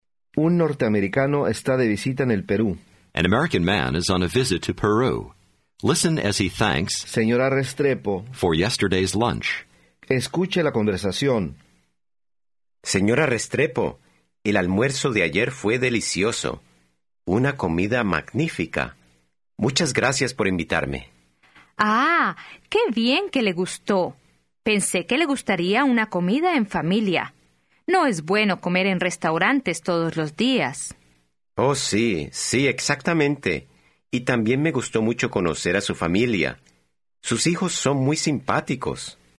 Аудио курс для самостоятельного изучения испанского языка.